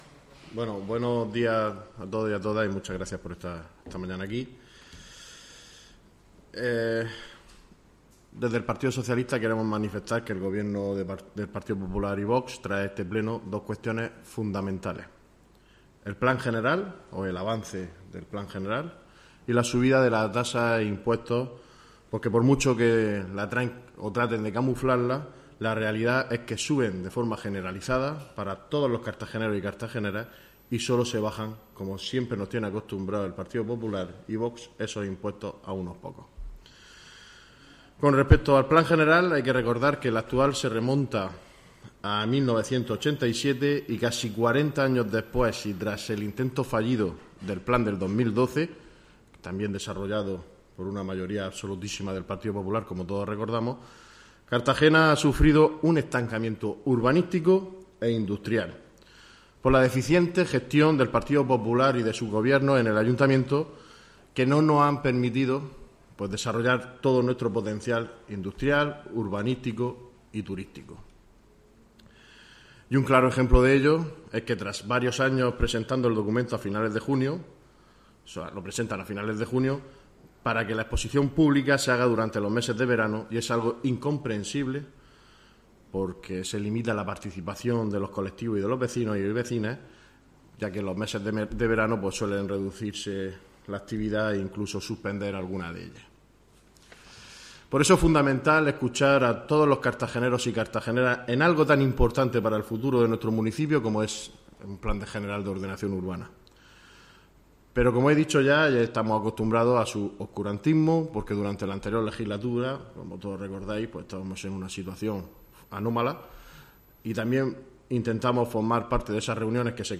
Audio: Rueda de prensa del PSOE.